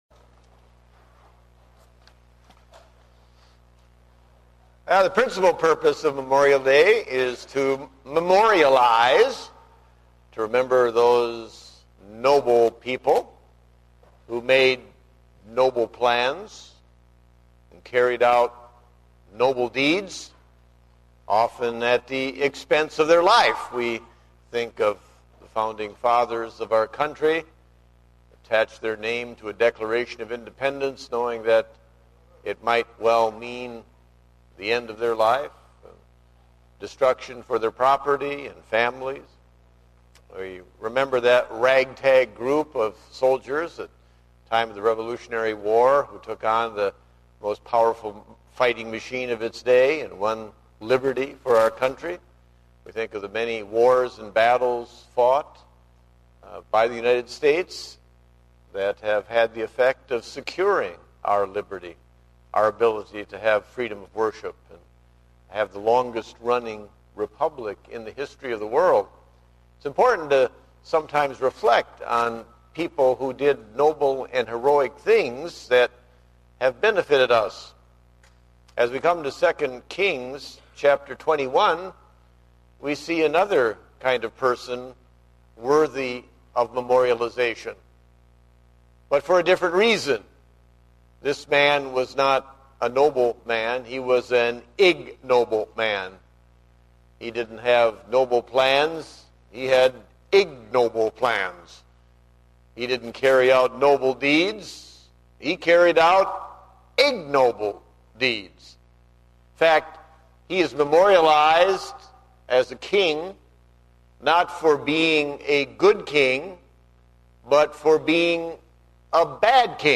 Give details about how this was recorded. Date: May 24, 2009 (Morning Service)